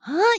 YX蓄力3.wav
YX蓄力3.wav 0:00.00 0:00.40 YX蓄力3.wav WAV · 34 KB · 單聲道 (1ch) 下载文件 本站所有音效均采用 CC0 授权 ，可免费用于商业与个人项目，无需署名。